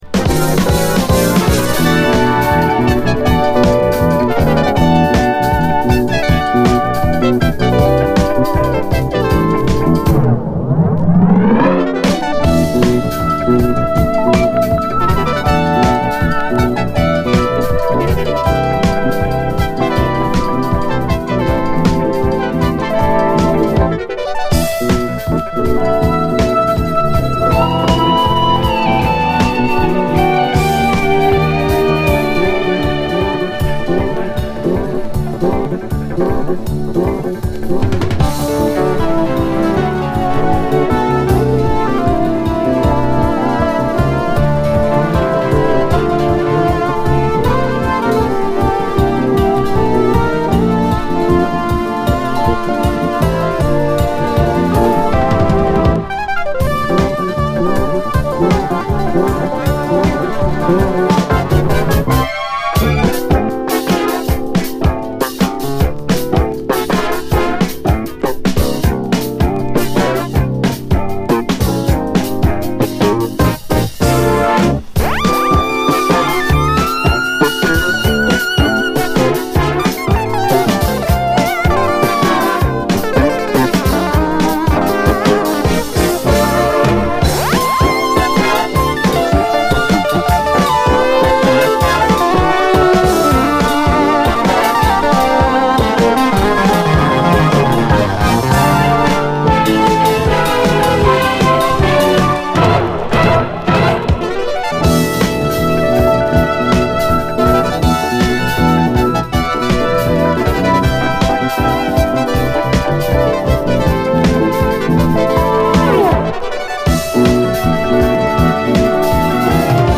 JAZZ FUNK / SOUL JAZZ, 70's ROCK, JAZZ, ROCK, 7INCH
ニューエイジ系のUKのプログレ〜フュージョン・バンド！
透明感あるシンセ・サウンドで疾走する高速ブラジリアン・フュージョン